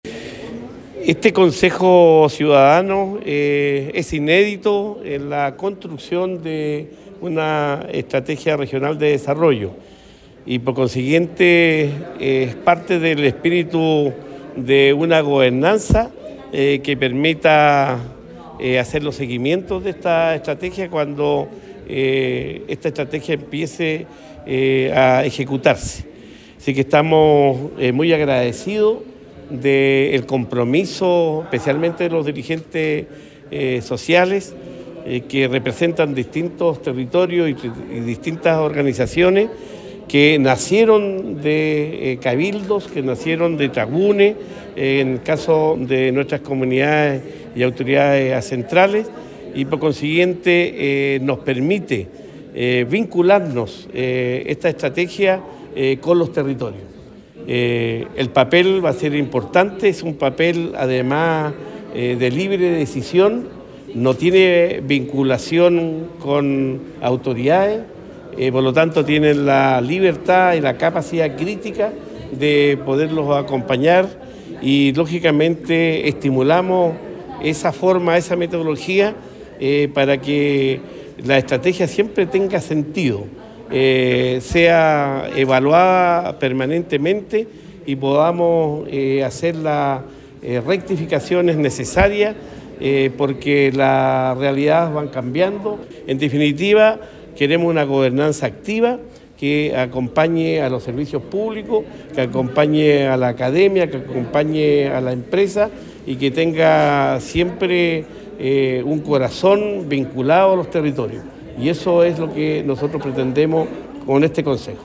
Cuña_Gobernador-Regiona_CONSEJO-CIUDADANO-ERD.mp3